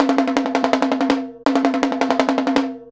Bongo_Roll_164.wav